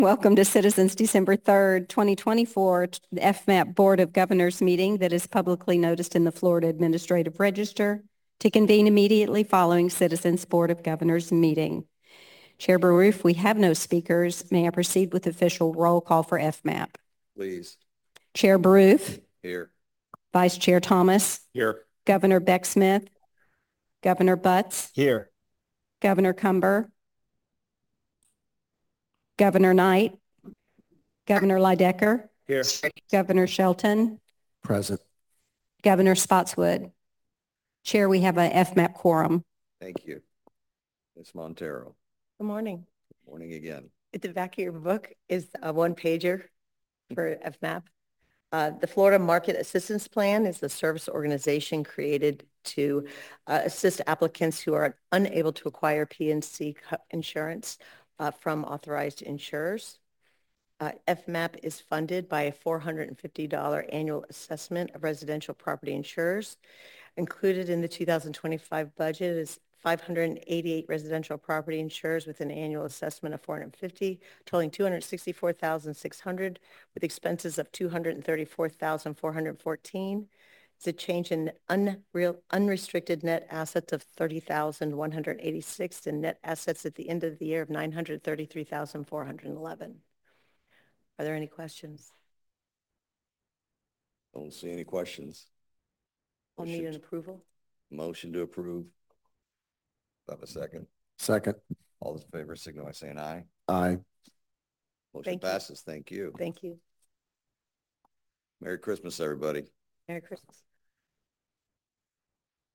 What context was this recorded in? Zoom Webinar Palmetto Marriott Teleconference